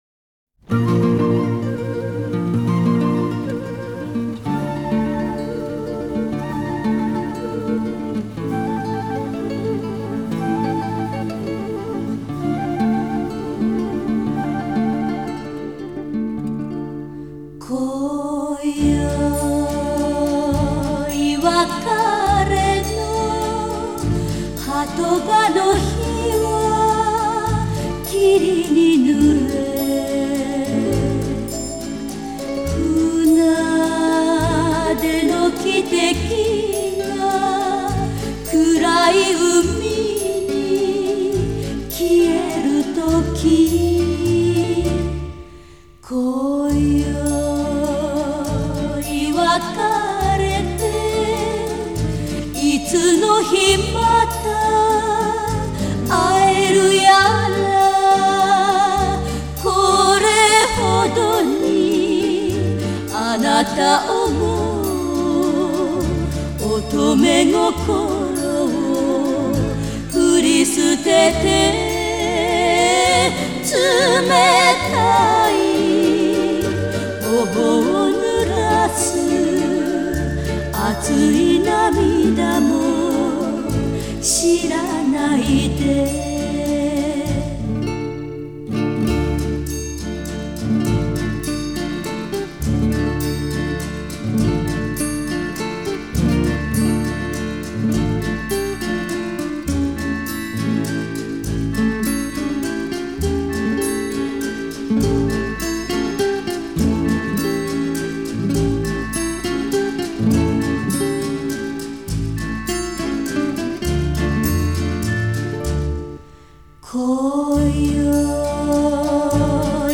Звук неплохой. Японский ремастеринг.
Жанр: Classic Pop